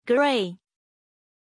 Pronunciation of Grey
pronunciation-grey-zh.mp3